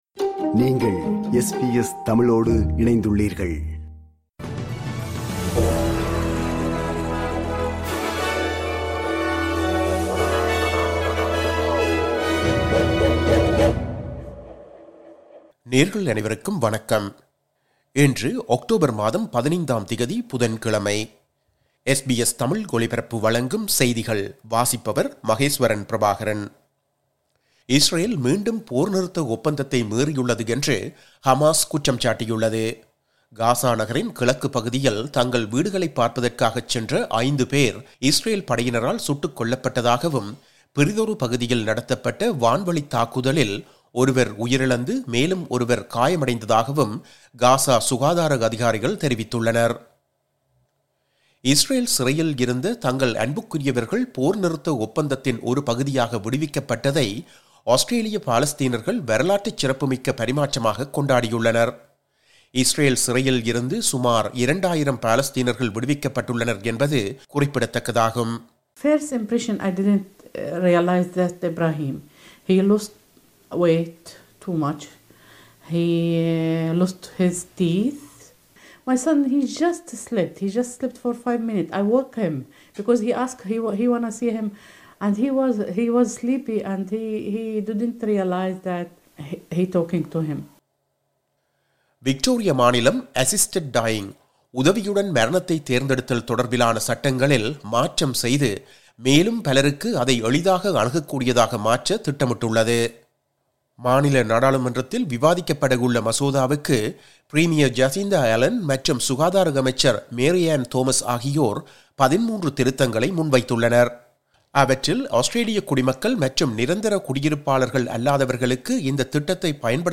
இன்றைய செய்திகள்: 15 அக்டோபர் 2025 புதன்கிழமை
SBS தமிழ் ஒலிபரப்பின் இன்றைய (புதன்கிழமை 15/10/2025) செய்திகள்.